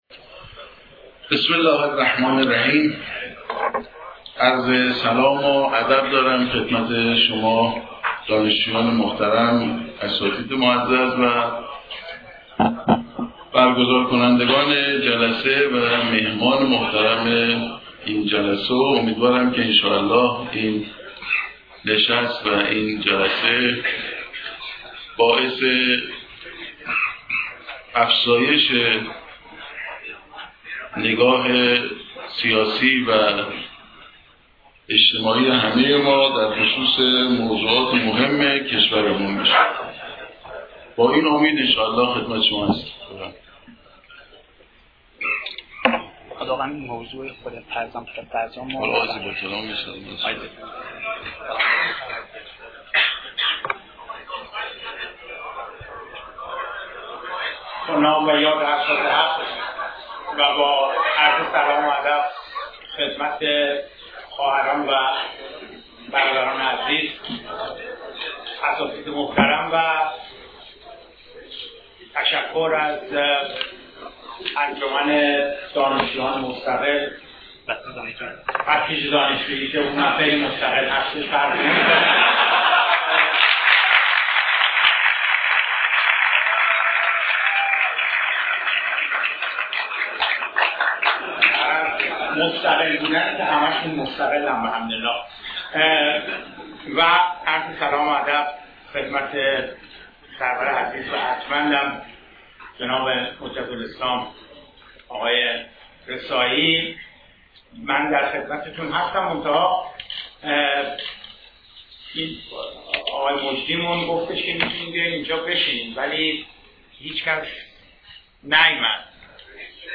گروه سیاسی رجانیوز:‌ هفته گذشته حجت الاسلام حمید رسایی و دکتر صادق زیباکلام در دانشگاه تربیت مدرس با موضوع «ایران پسابرجام» مناظره کردند.